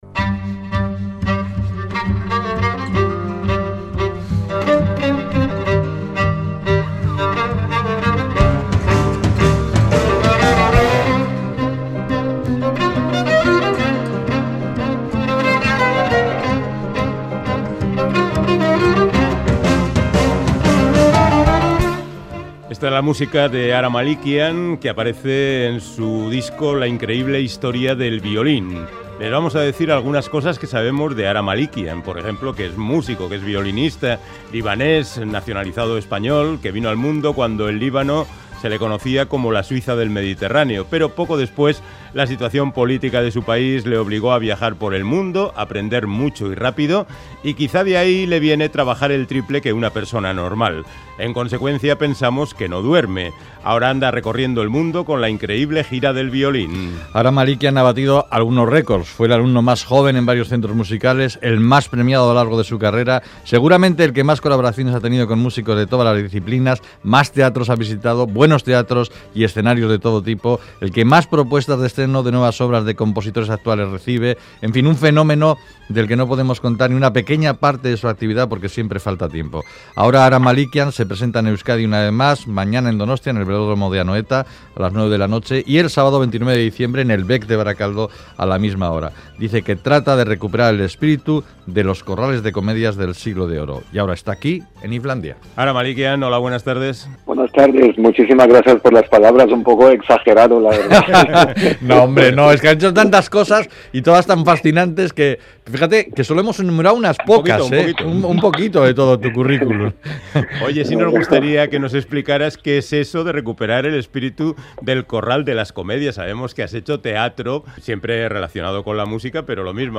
Audio: Charlamos con el violinista hispano-libanés Ara Malikian, que visita Donostia, Iruña y Bilbao con su gira La increíble gira del violín